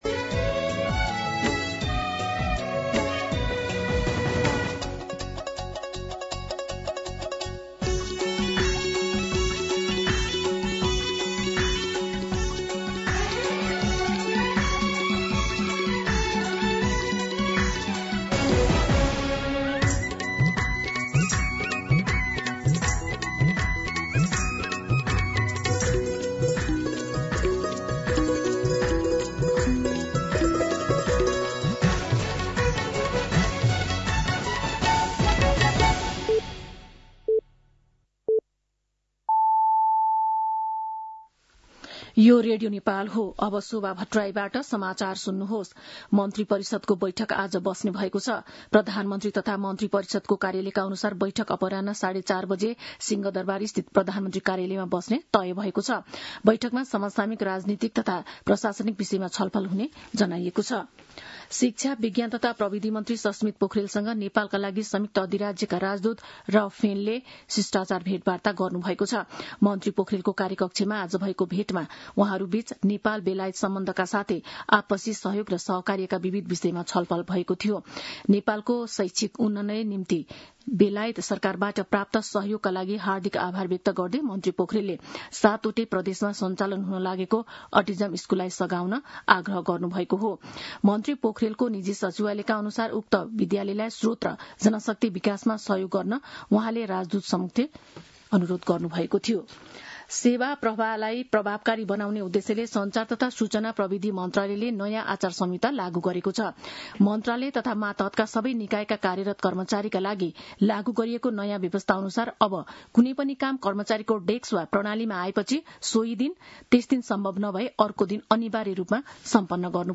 दिउँसो १ बजेको नेपाली समाचार : २ वैशाख , २०८३